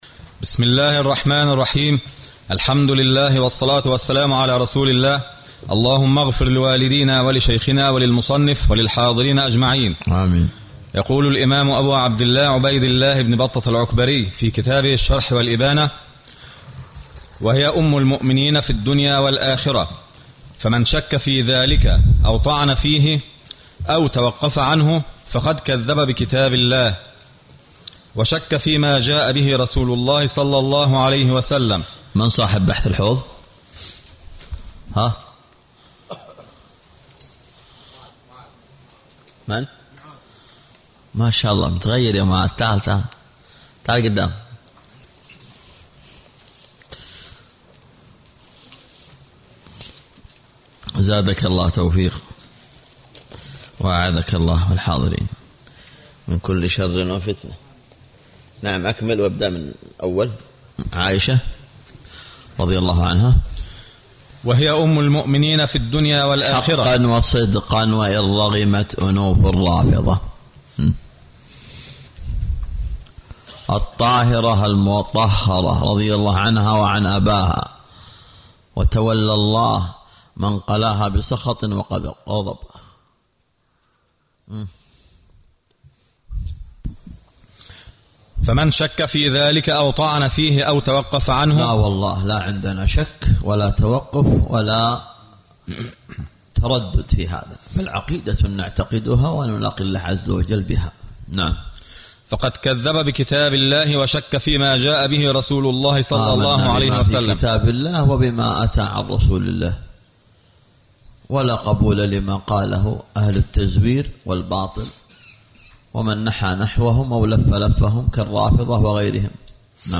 20- الدرس العشرونَ